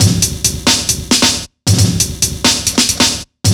Nw Break 135.wav